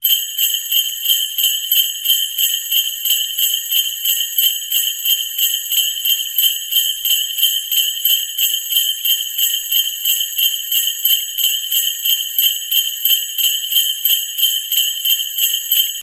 Санта Клаус мчится на санях